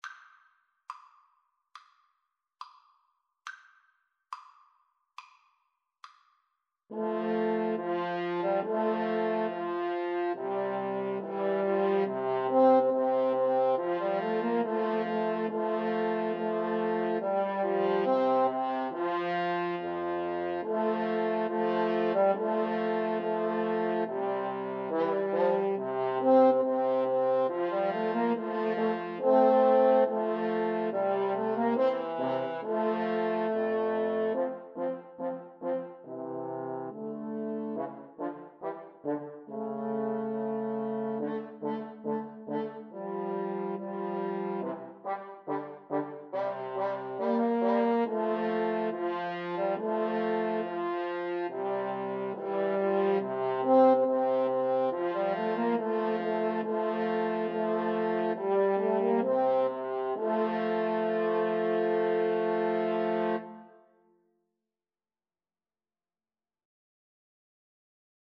Free Sheet music for French Horn Trio
Play (or use space bar on your keyboard) Pause Music Playalong - Player 1 Accompaniment Playalong - Player 3 Accompaniment reset tempo print settings full screen
Andante maestoso
C major (Sounding Pitch) (View more C major Music for French Horn Trio )
Classical (View more Classical French Horn Trio Music)